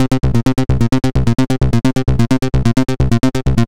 Hard As Nails Cm 130.wav